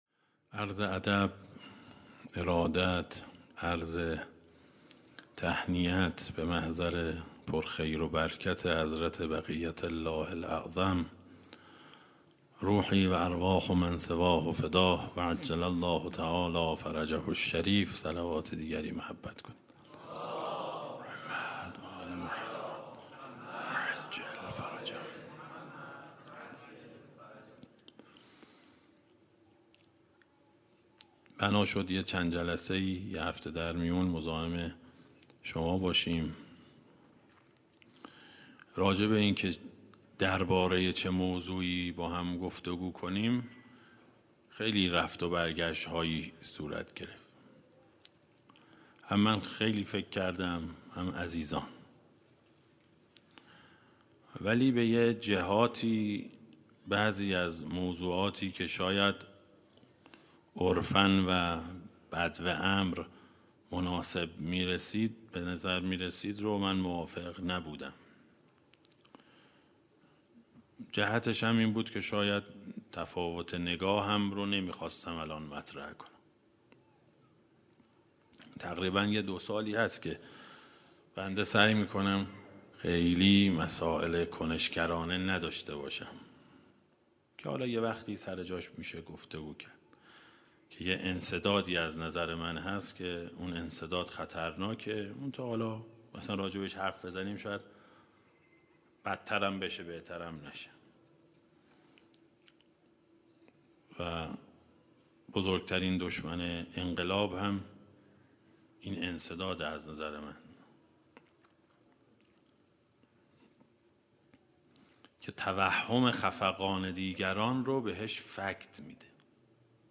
جلسه هفتگی | ولادت پیامبر اکرم صلی‌الله‌علیه‌وآله‌وسلم و امام ...
سخنرانی